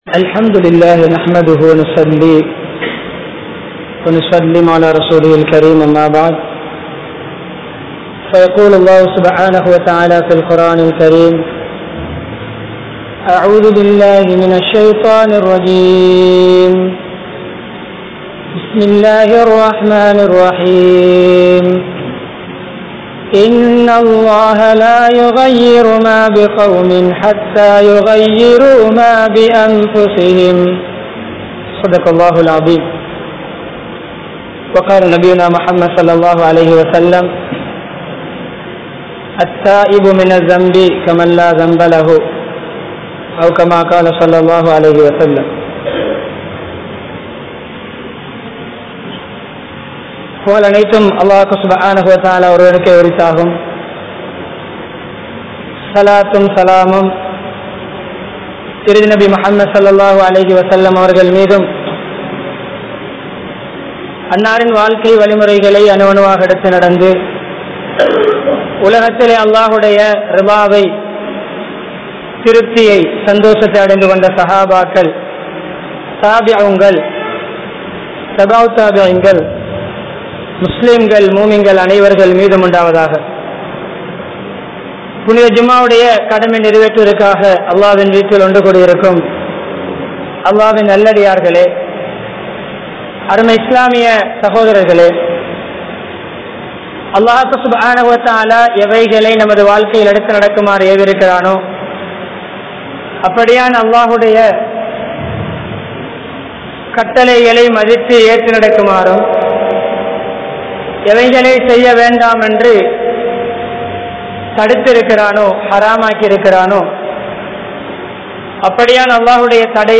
Wattala, Mabola, Duwatta Jumua Masjidh